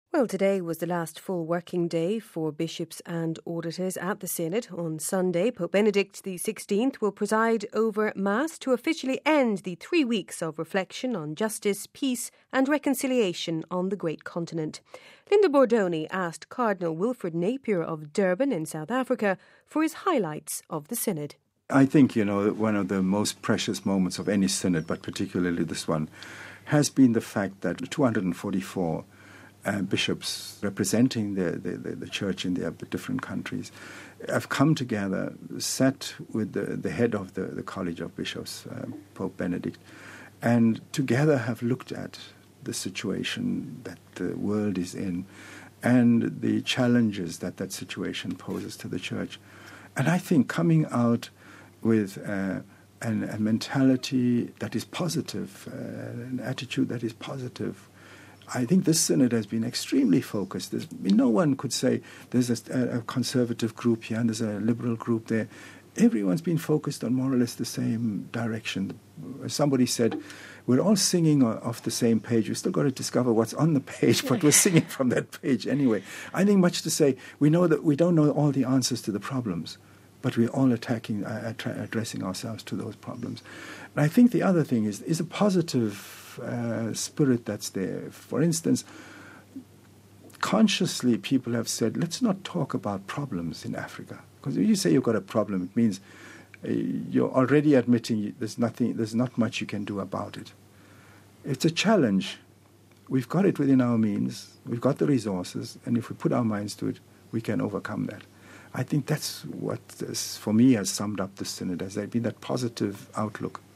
Cardinal Napier Shares Synod Highlights